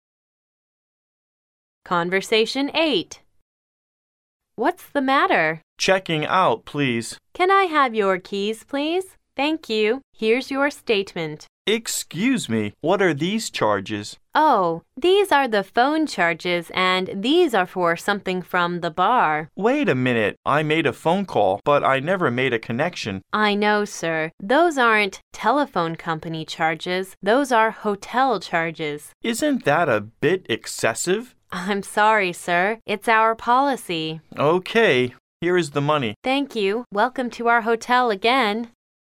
Conversation 8